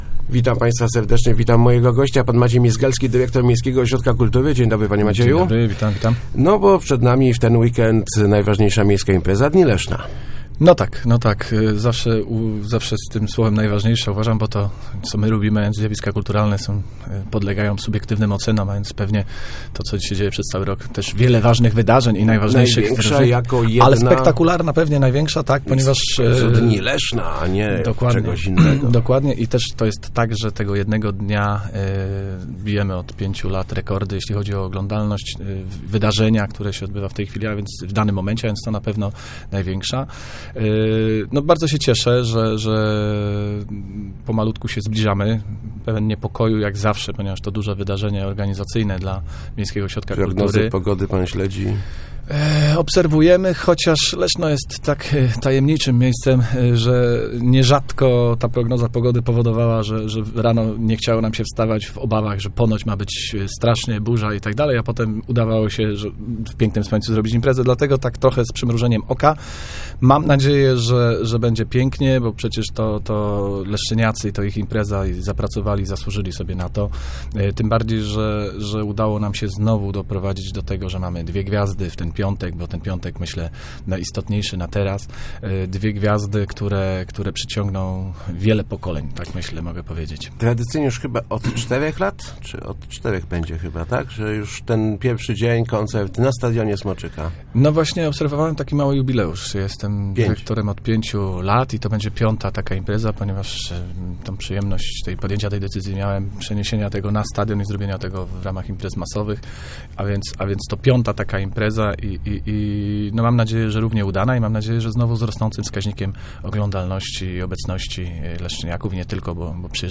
Start arrow Rozmowy Elki arrow Dni Leszna 2015